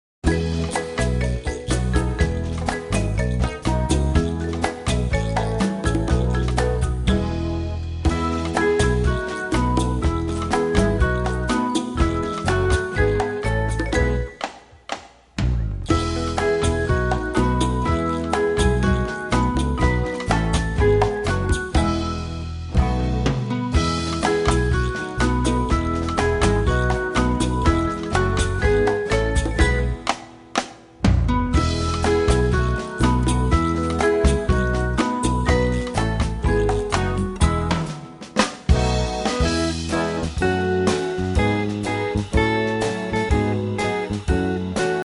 Listen to a sample of the instrumental